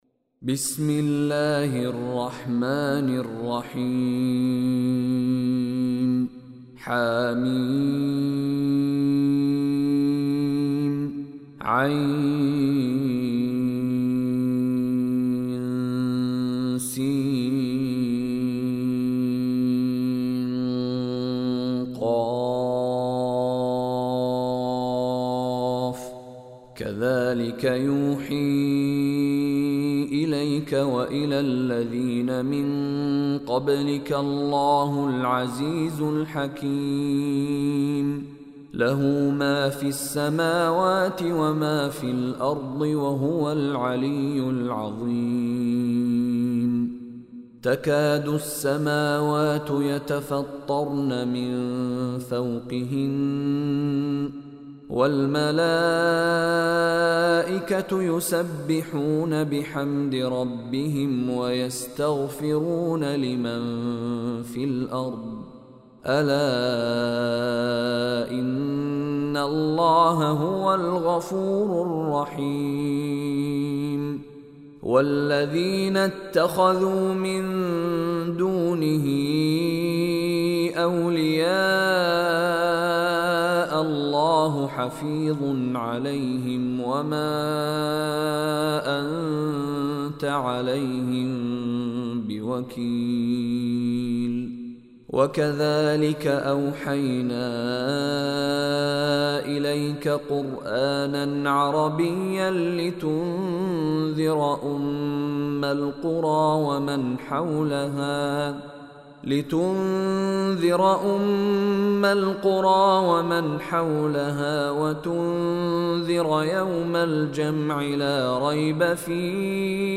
Surah Ash-Shura Recitation by Mishary Rashid
Surah Ash-Shura is 42nd chapter / Surah of Holy Quran. Surah Ash-Shura listen online and download mp3 tilawat / recitation in the voice of Sheikh Mishary Rashid Alafasy.